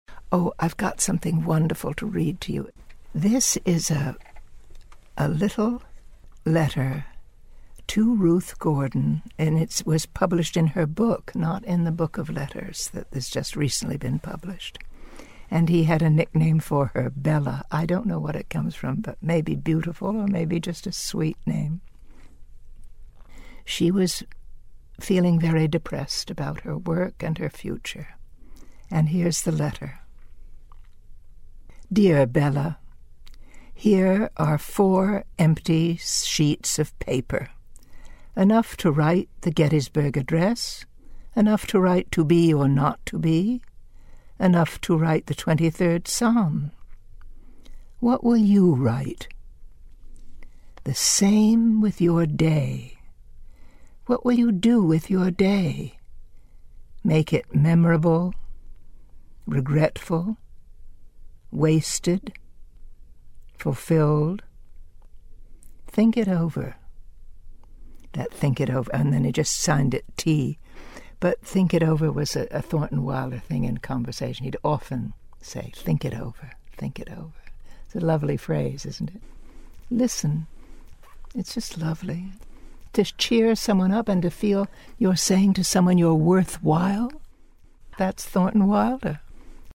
Actress Marian Seldes shares a letter that author Thornton Wilder sent to actress/writer Ruth Gordon. [1:29]